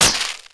wrench_hit_glass1.wav